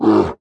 client / bin / pack / Sound / sound / monster / bear / damage_1.wav
damage_1.wav